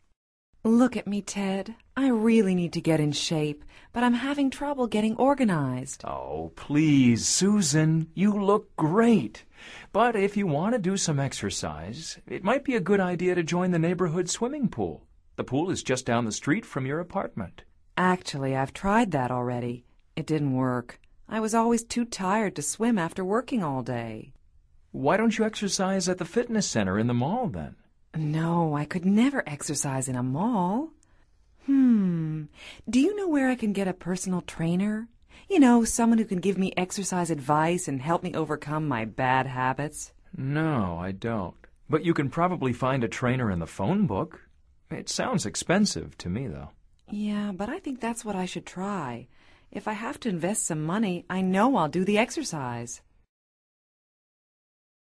Escucha atentamente esta conversación entre Susan y Ted y selecciona la respuesta más adecuada de acuerdo con tu comprensión auditiva.